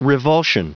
Prononciation du mot revulsion en anglais (fichier audio)
Prononciation du mot : revulsion